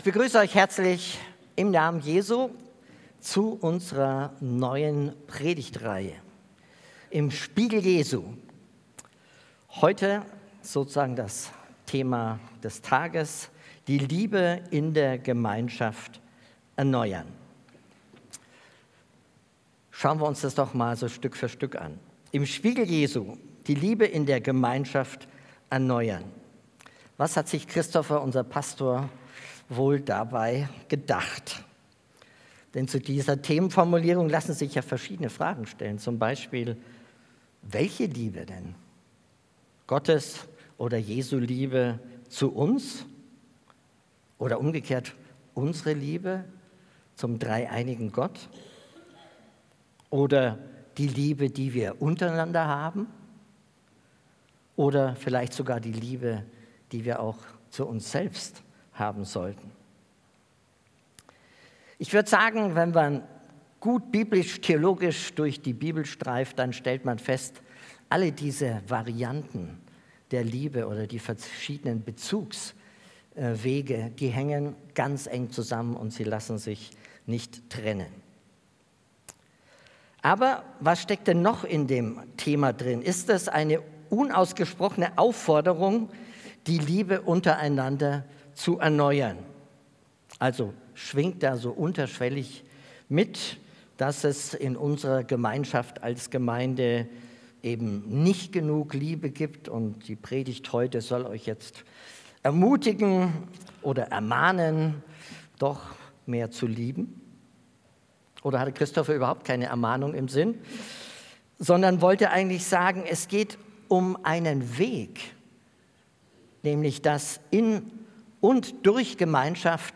Gottesdienst
Predigt